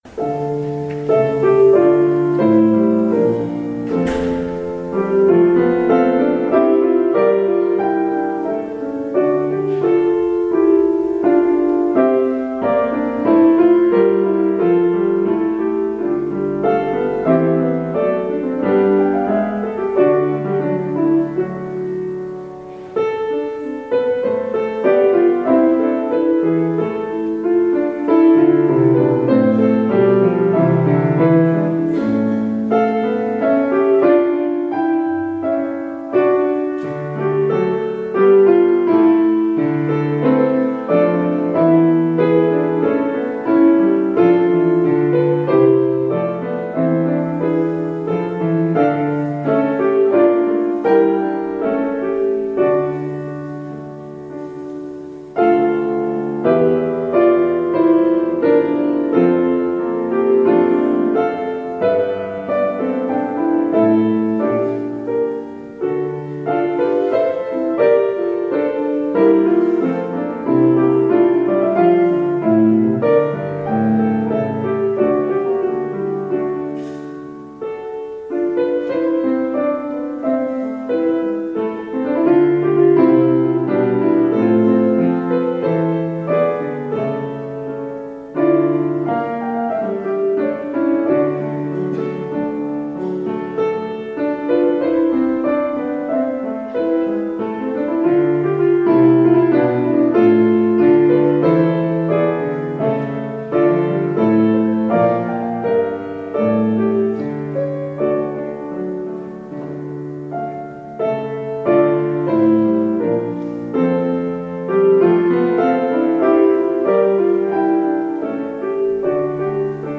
Audio recording of the 10am service (Fourth Sunday)